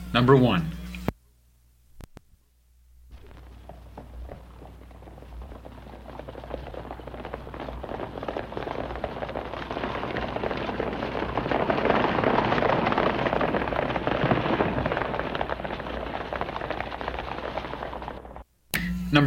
复古马车 " G5201 舞台马车
描述：驿马车快速上路，有很多马驰骋，一般叮叮当当。远距离开始和退出。
我已将它们数字化以便保存，但它们尚未恢复并且有一些噪音。